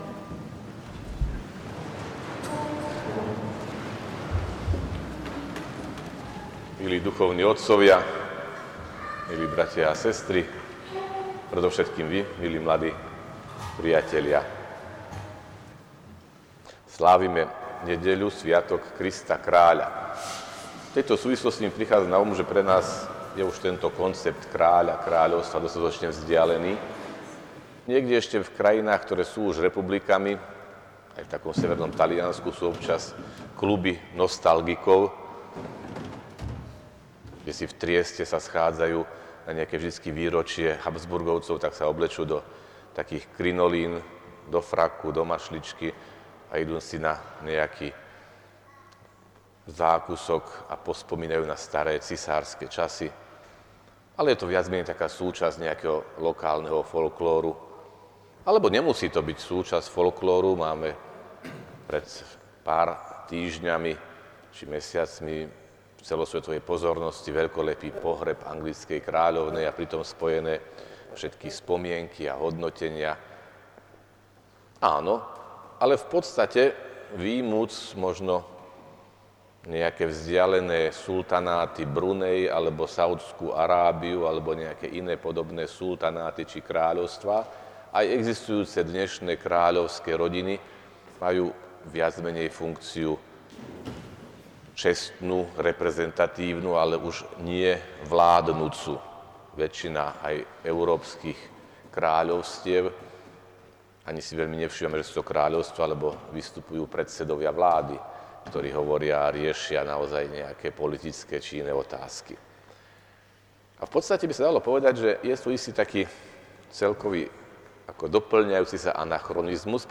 Liturgickým vrcholom nedele bola archijerejská svätá liturgia v bazilike, ktorú slúžil arcibiskup Cyril Vasiľ, košický eparchiálny biskup.